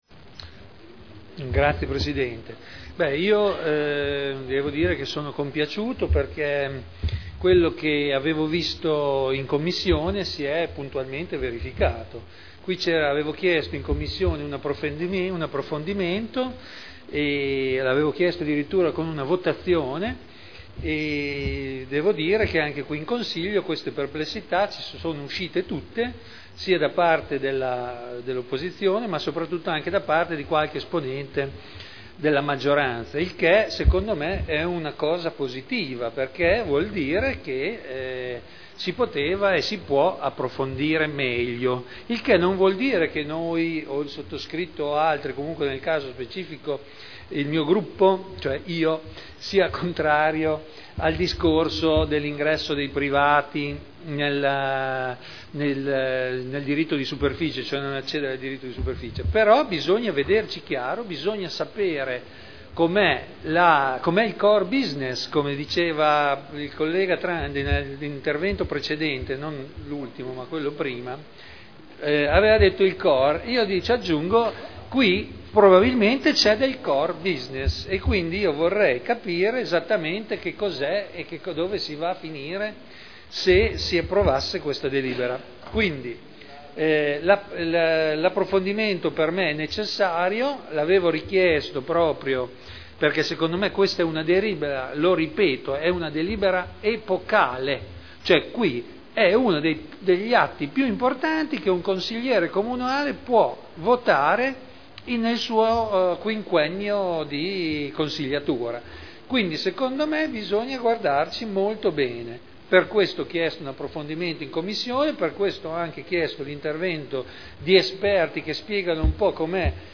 Seduta del 13/12/2010 Deliberazione: Approvazione degli indirizzi per la concessione in diritto di superficie di aree comunali Dichiarazioni di voto